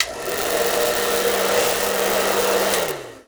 Blow Dryer 03
Blow Dryer 03.wav